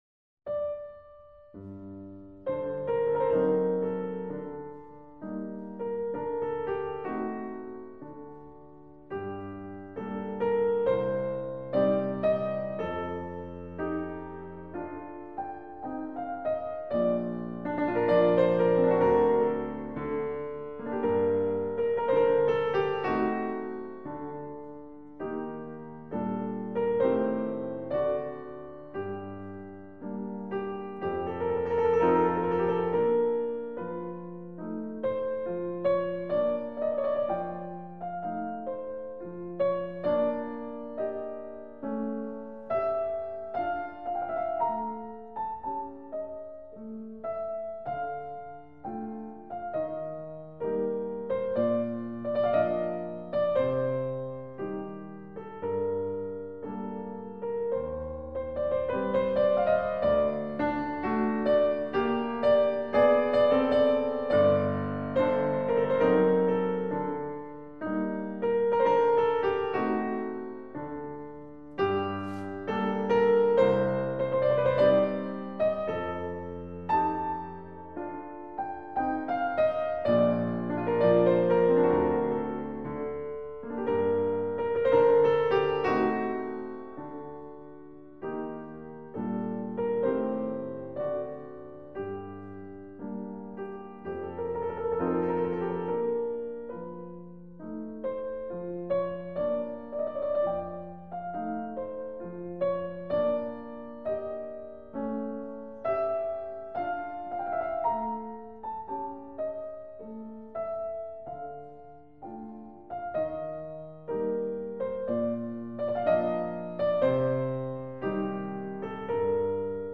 Музыка для релаксации